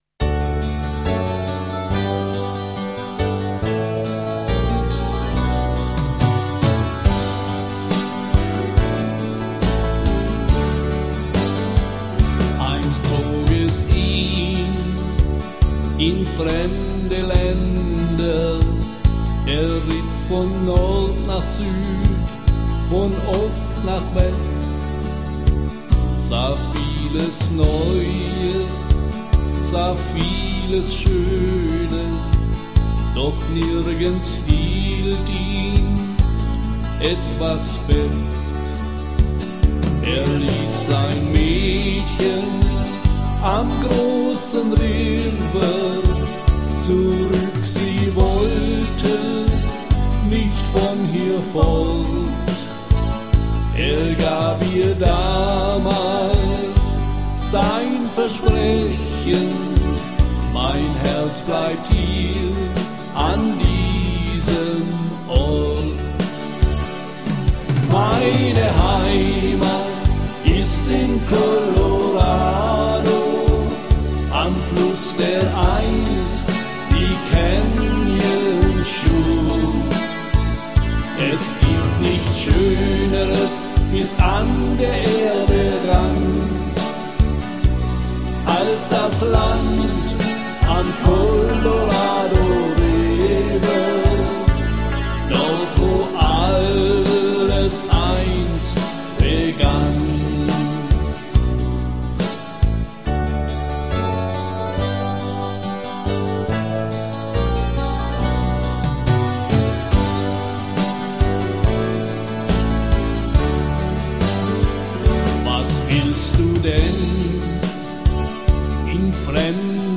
Meine Heimat ist am Colorado (Schlager)